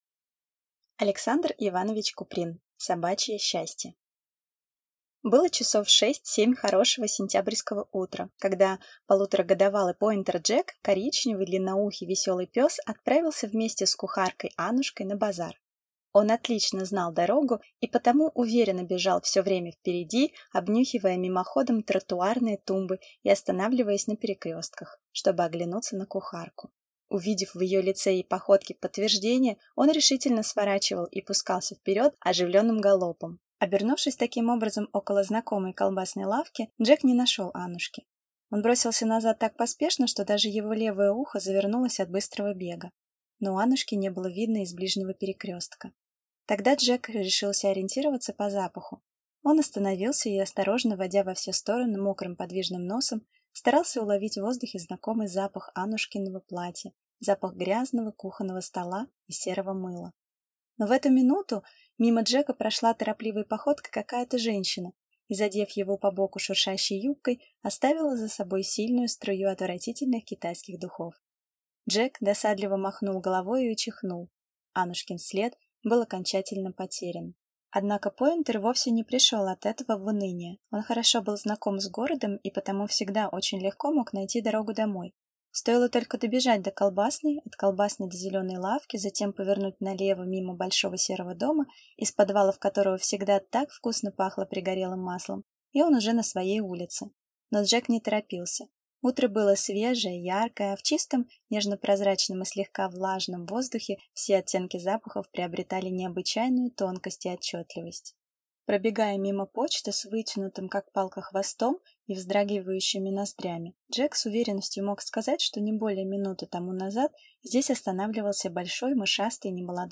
Аудиокнига Собачье счастье | Библиотека аудиокниг